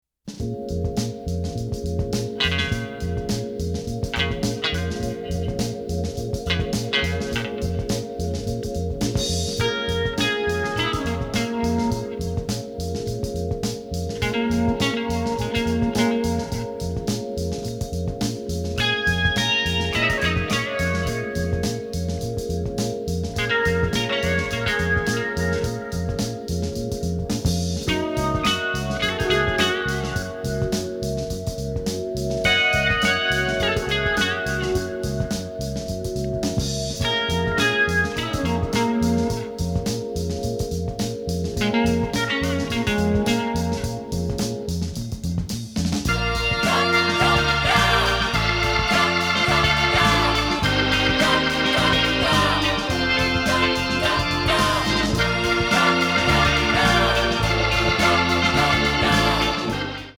and the result was an album recorded mostly in New York City
rocksteady bass